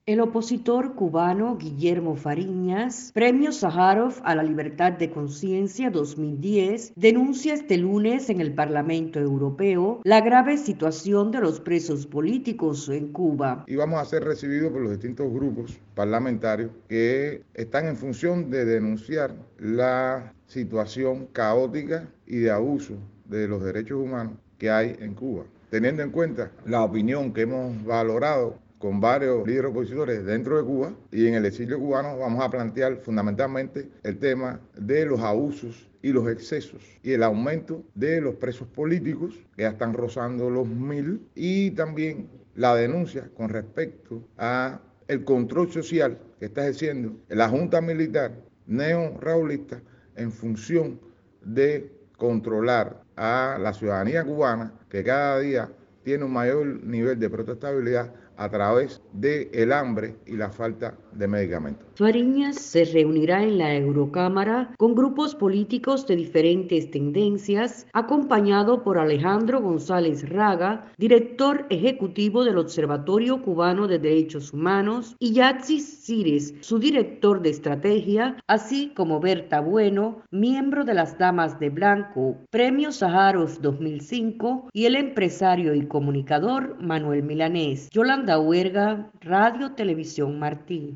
Declaraciones de Guillermo Fariñas a Radio Martí